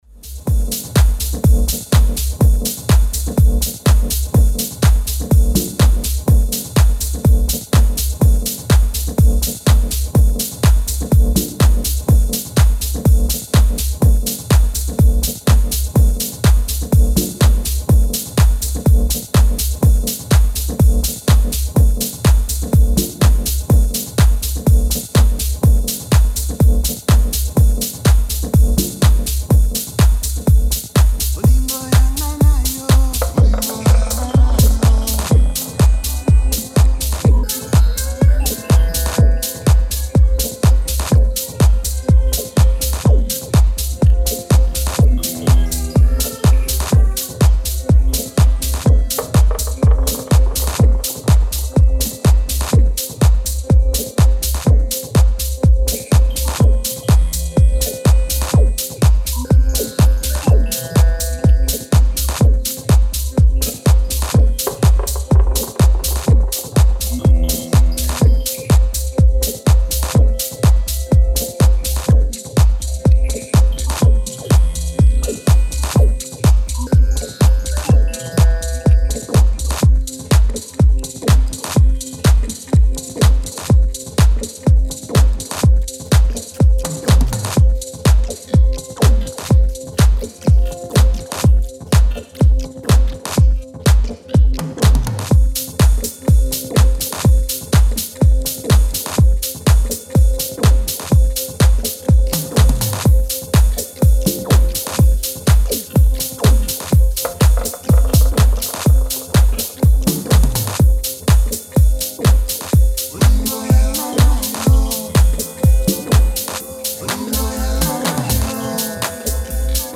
Style: Techno / Minimal